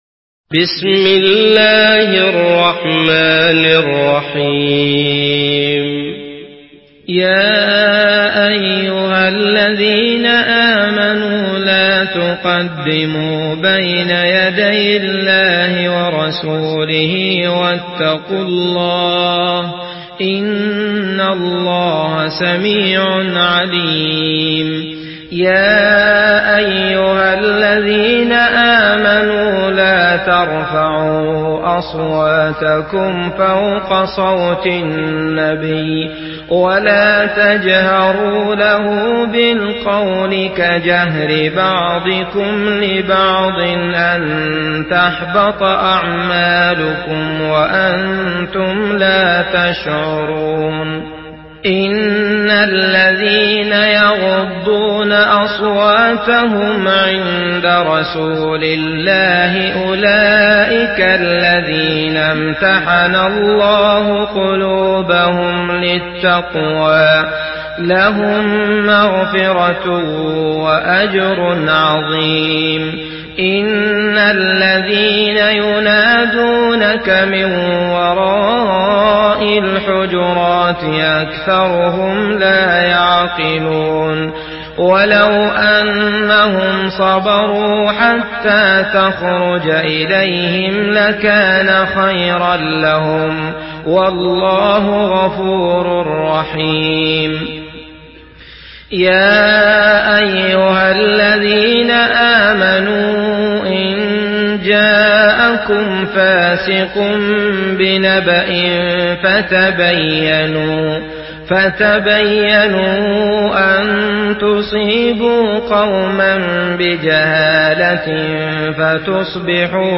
سورة الحجرات MP3 بصوت عبد الله المطرود برواية حفص
مرتل